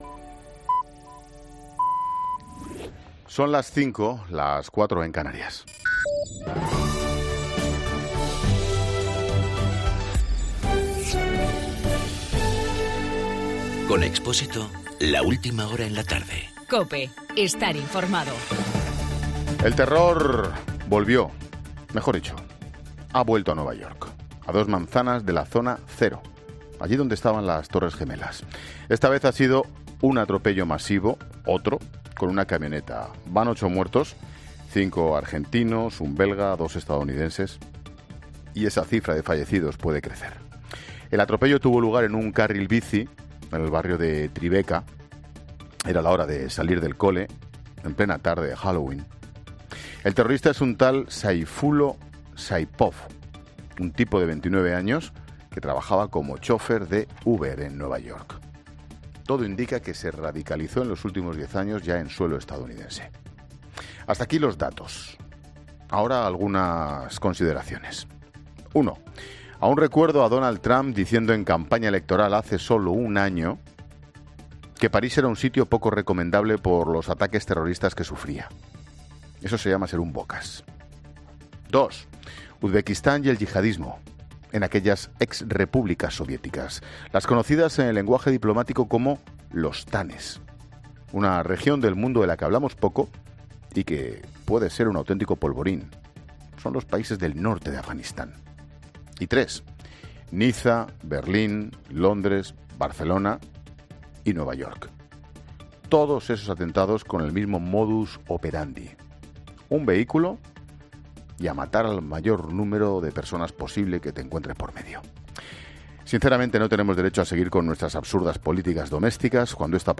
Monólogo de Expósito
Ángel Expósito analiza en su monólogo de las 17 horas el atentado terrorista de Nueva York.